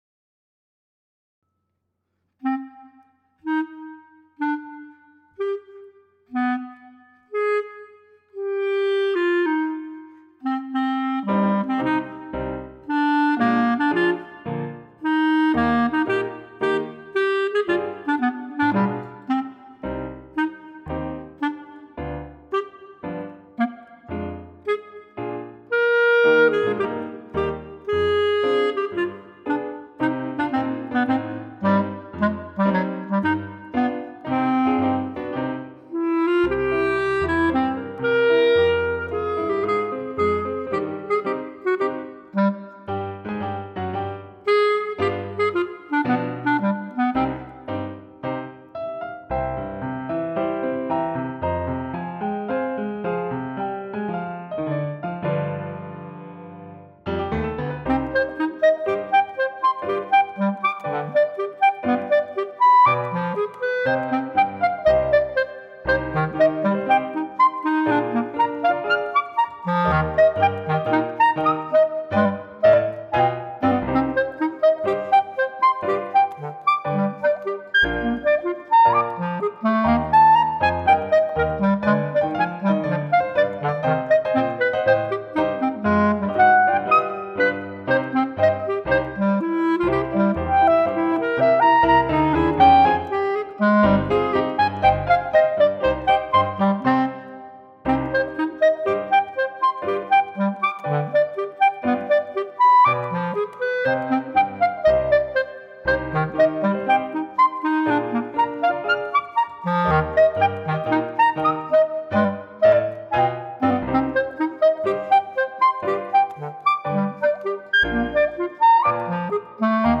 for Bb Clarinet & Piano.